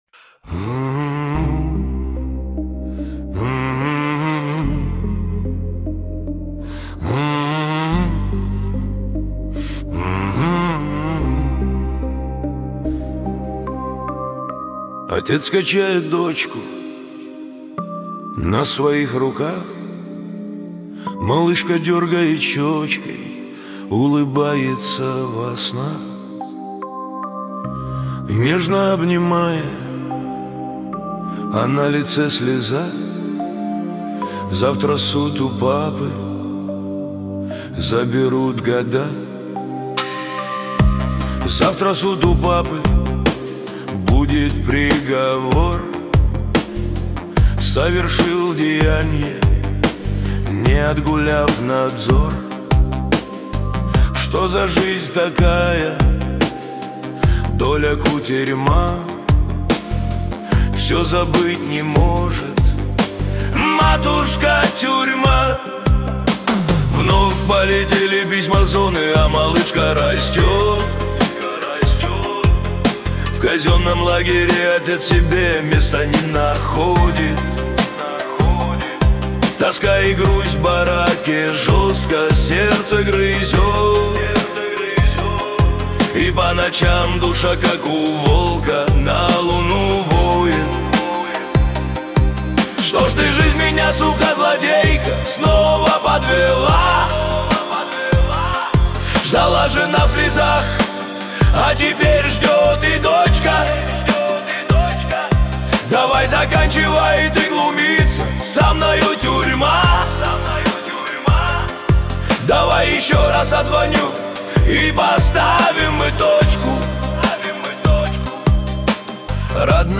Качество: 320 kbps, stereo
Поп музыка, Нейросеть Песни 2025